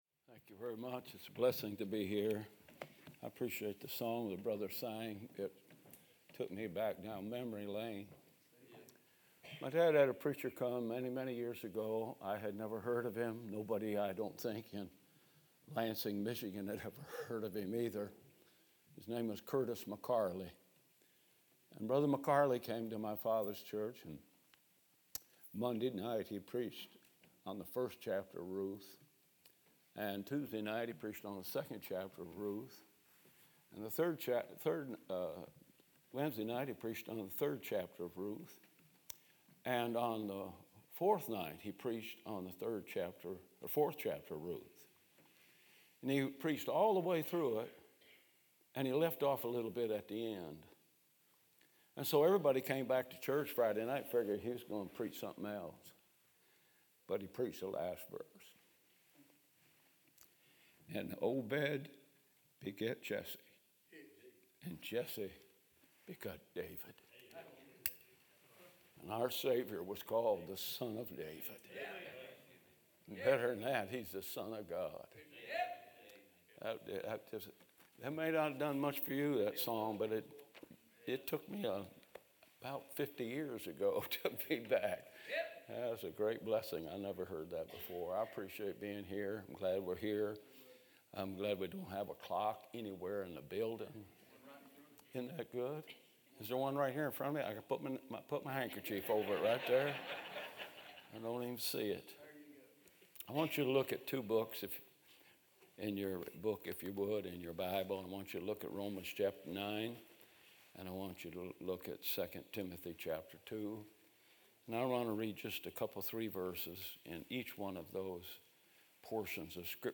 sermons
From Series: "2025 Spring Revival"